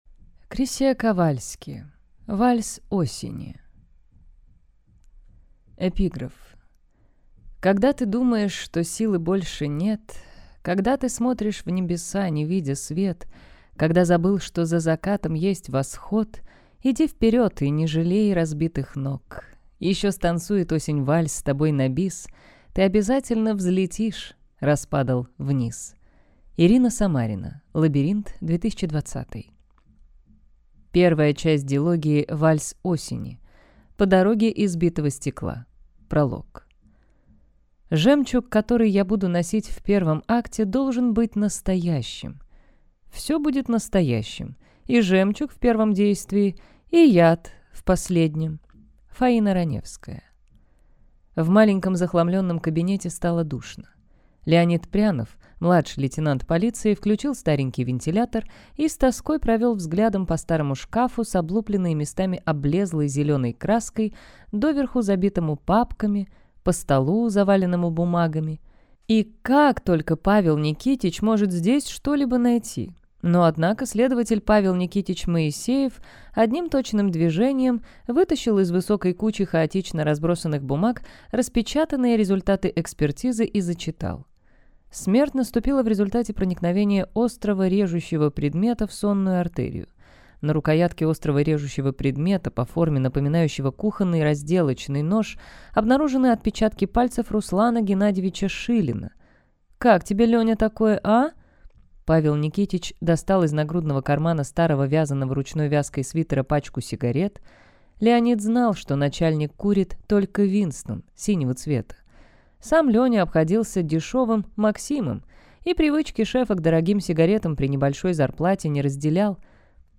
Аудиокнига Вальс осени | Библиотека аудиокниг